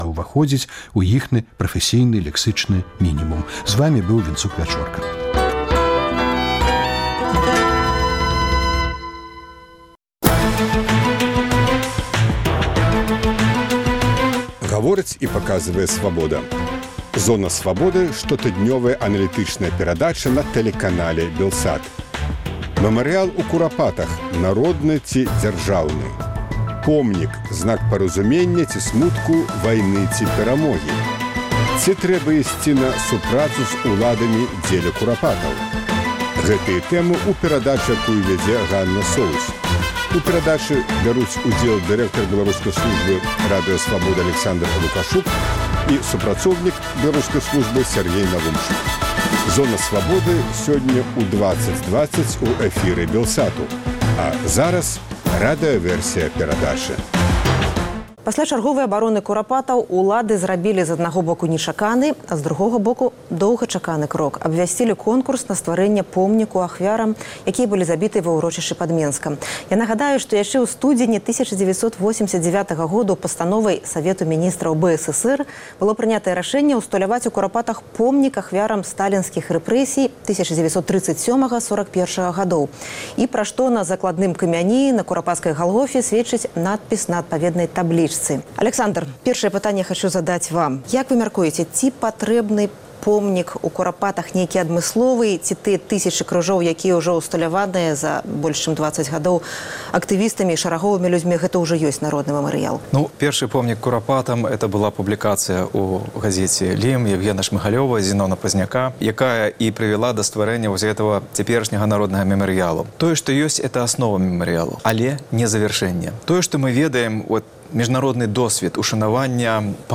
Штотыднёвая перадача Радыё Свабода на тэлеканале «Белсат».